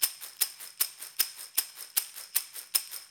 AFP SHAKER.wav